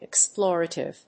音節ex・plor・a・tive 発音記号・読み方
/ɪksplˈɔːrəṭɪv(米国英語)/